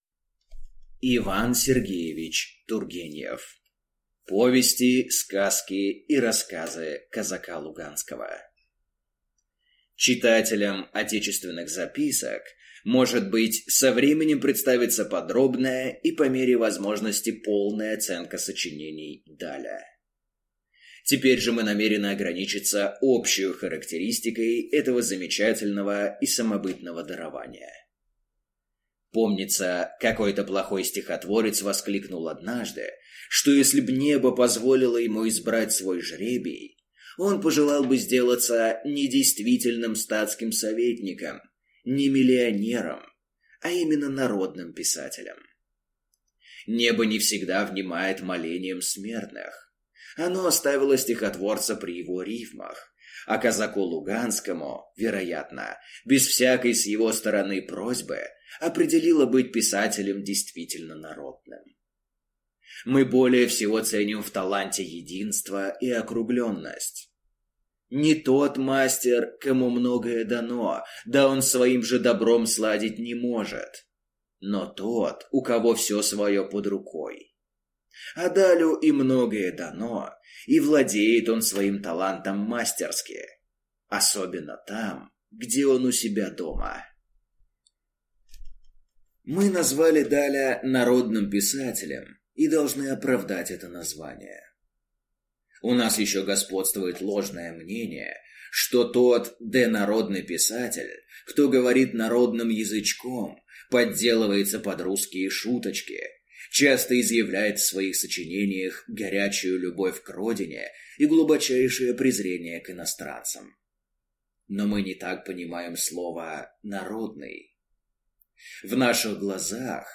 Аудиокнига Повести, сказки и рассказы Казака Луганского | Библиотека аудиокниг